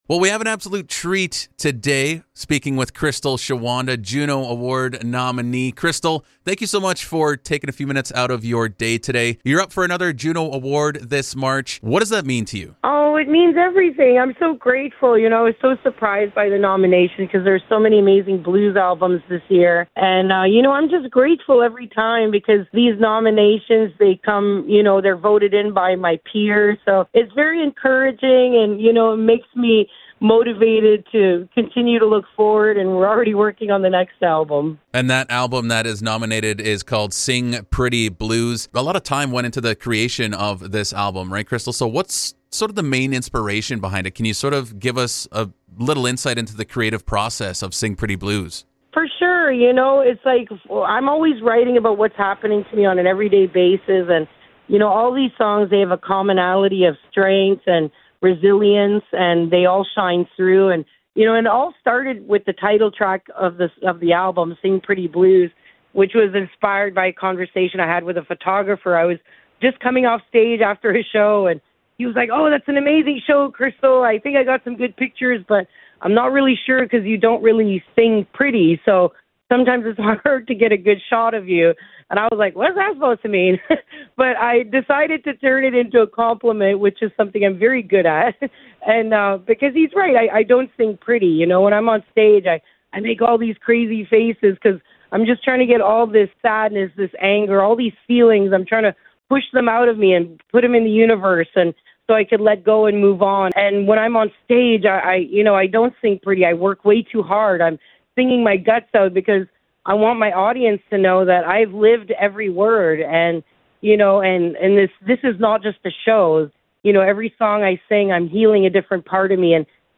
Crystal Shawanda speaks on Juno nomination for Sing Pretty Blues